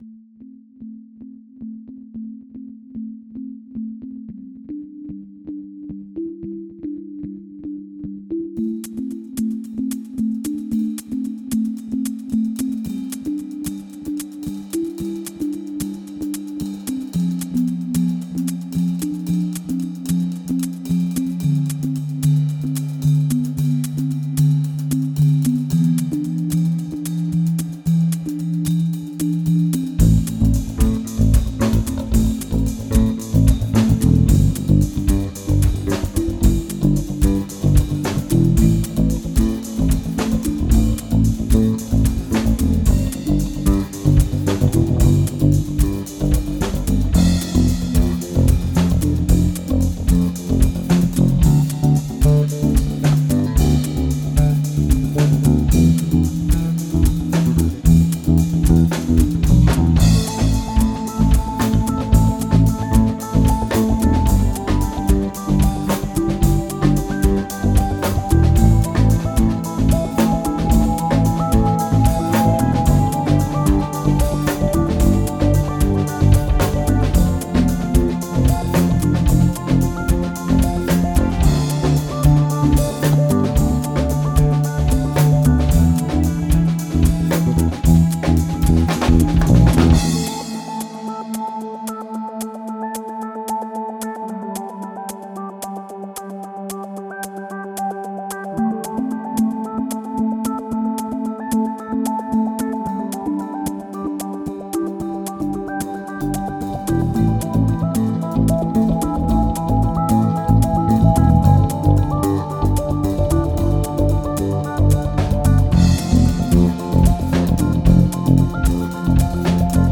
That's a couple Rapture instances with some strings and a Roland synth for some electronic percussion. The bass and drums are performed live.
The only problem was that about 3:30 into the song, the mix just deteriorated.
This song is really funky and tight.  The tone of the bass is sharp and right on.
This songs pulsed in a good way.
The drums were tracked with the only stereo bit being the XY configured overheads, so there's not too much I can do about the width of the image without resorting to some effects or something.